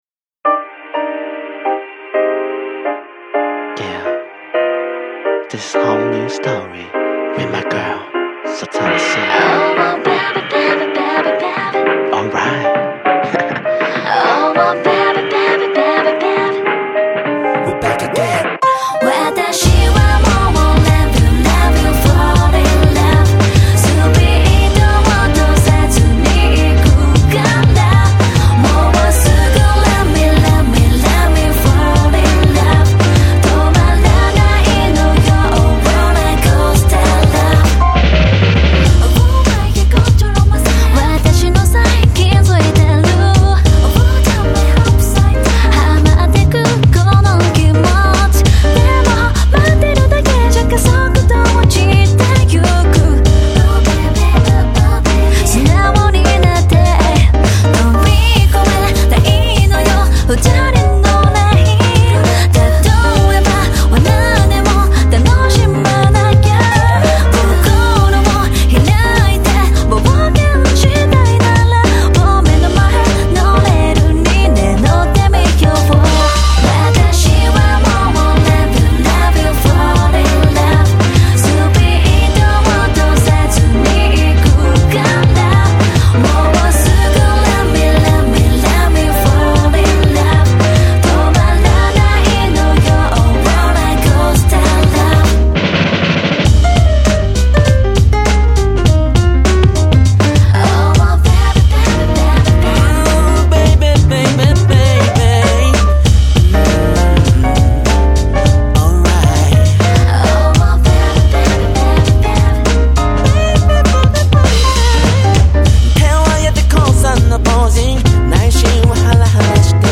11' Super Nice Japanese R&B !!